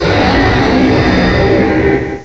cry_not_guzzlord.aif